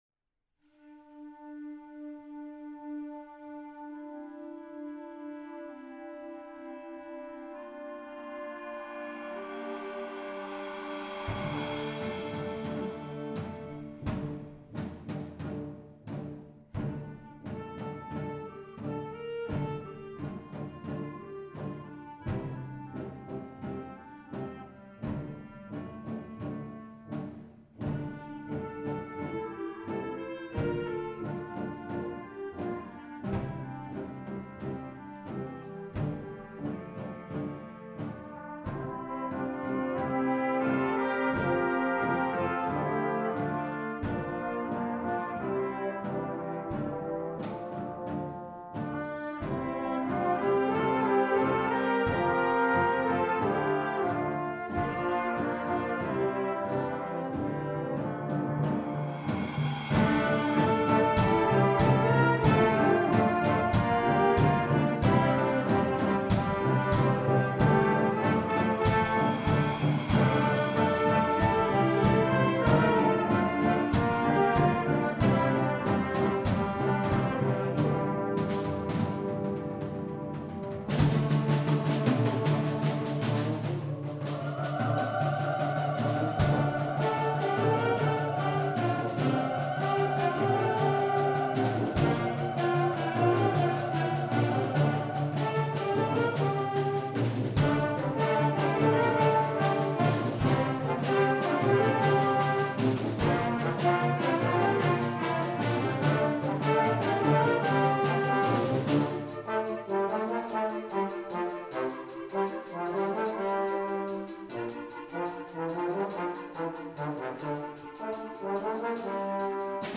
Schulorchester
Konzertwertung 2015, Riedau, Pramtalsaal